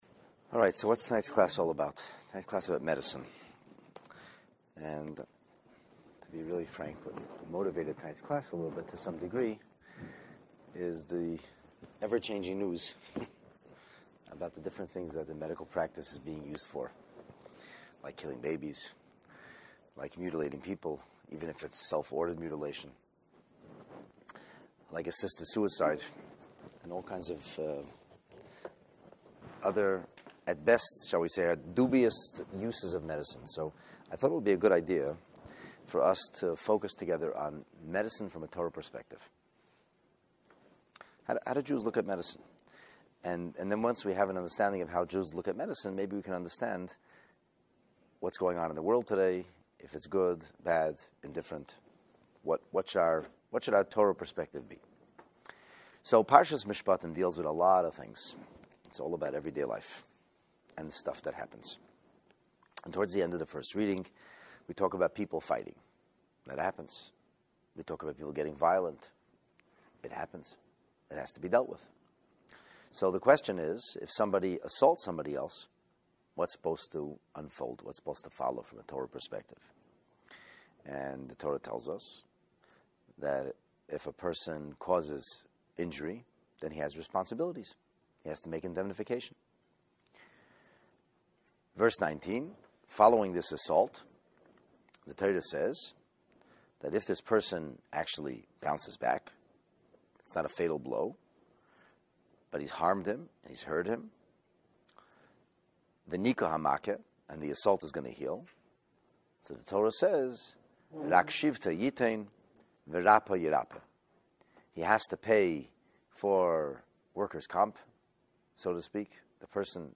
A penetrating presentation on the ethical practice of medicine, ultimately addressing questions like: if G-d is the ultimate Healer, why are we permitted to heal, and is the practice of medicine a moral obligation? The entire lecture is ultimately based on a fuller and proper understanding of but two words of Scripture, which speak to us in the context of the obligation to indemnify victims of assault and battery.